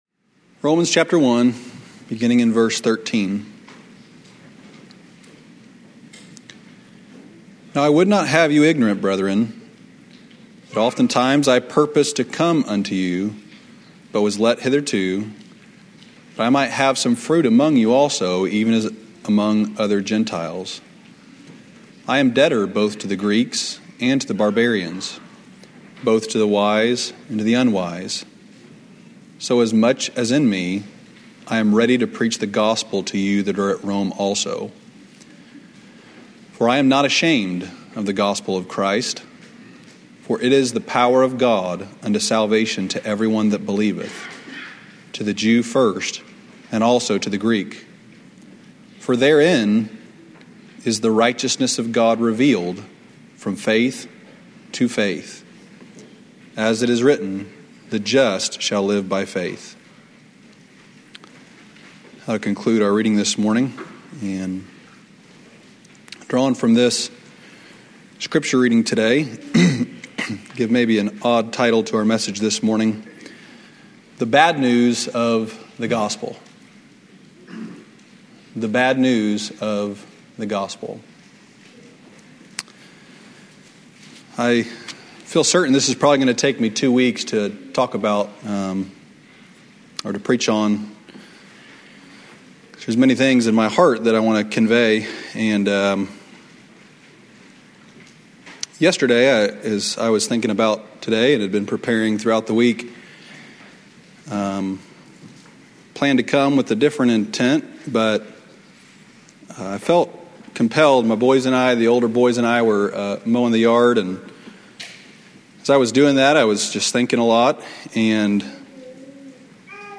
2025 Friday morning devotional from the 2025 session of the Old Union Ministers School.